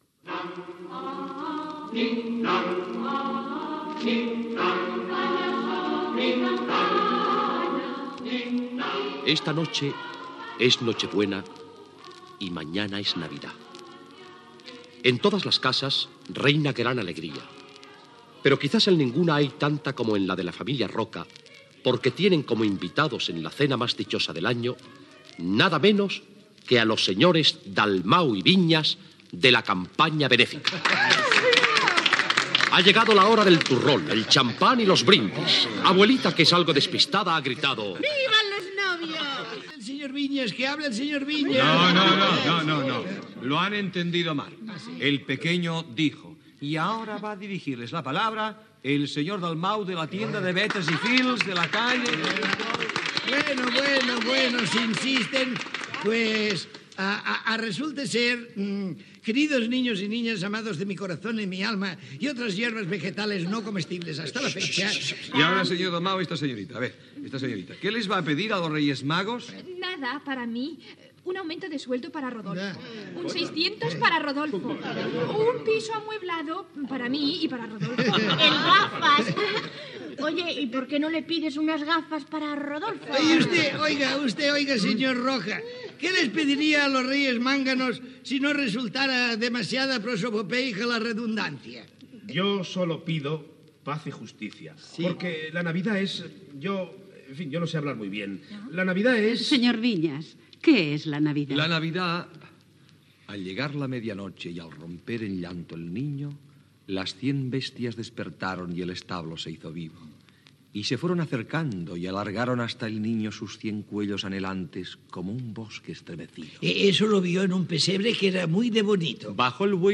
Extret de "Disco de Navidad: pro campaña benéfica de Radio Nacional de España en Barcelona"(Belter,1964).